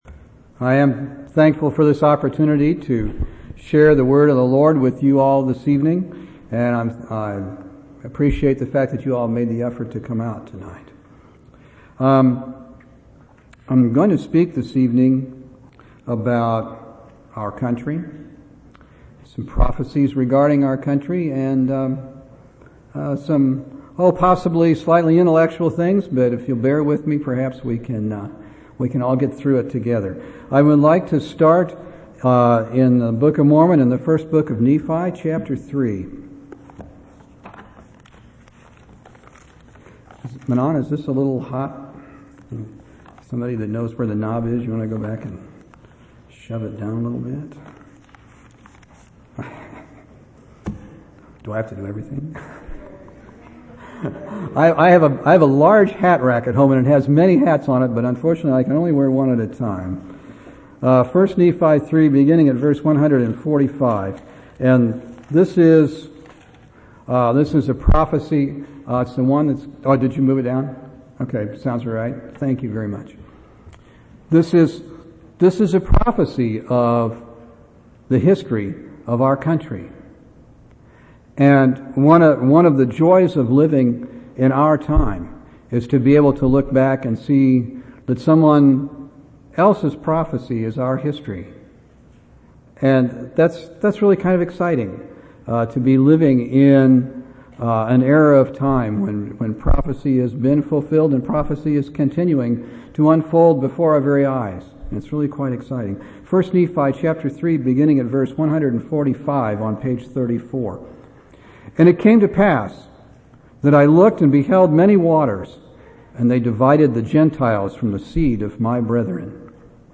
7/4/2004 Location: Temple Lot Local Event